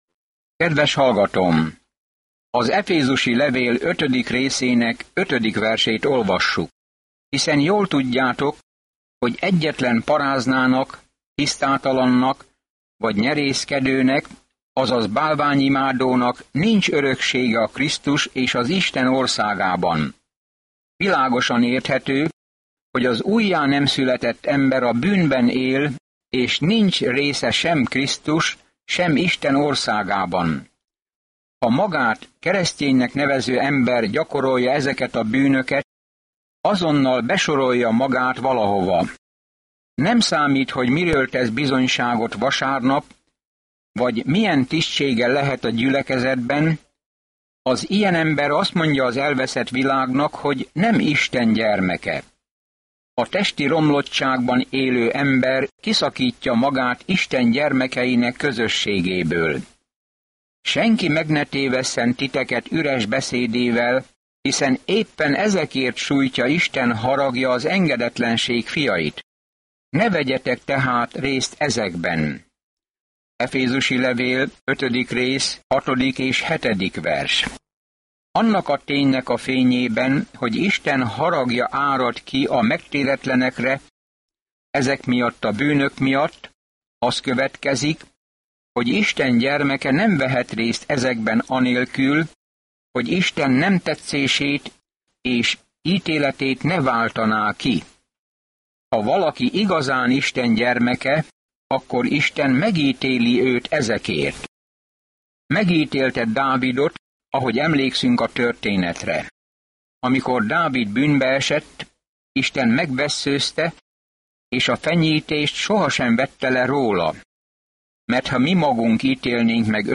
Szentírás Efezus 5:5-19 Nap 19 Olvasóterv elkezdése Nap 21 A tervről Az efézusiakhoz írt levél elmagyarázza, hogyan kell Isten kegyelmében, békéjében és szeretetében járni, a csodálatos magasságokból, hogy mit akar Isten gyermekei számára. Napi utazás az efézusi levélben, miközben hallgatod a hangos tanulmányt, és olvasol válogatott verseket Isten szavából.